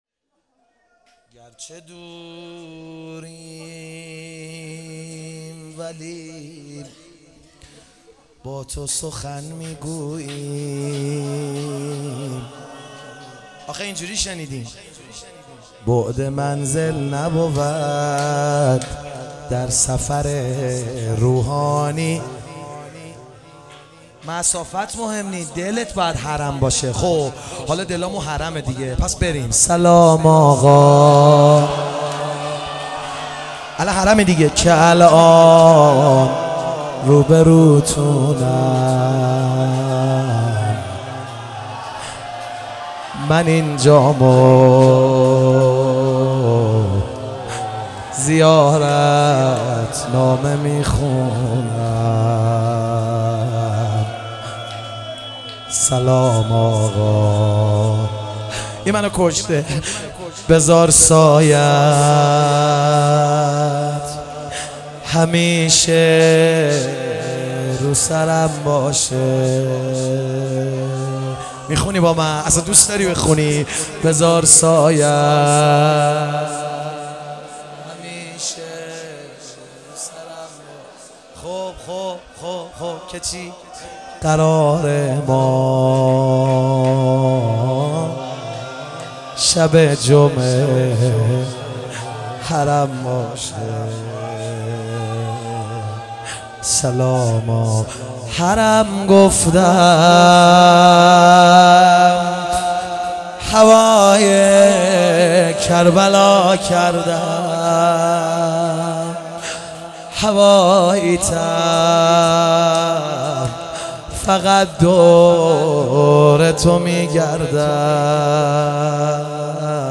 شهادت حضرت ام البنین(س)97 - روضه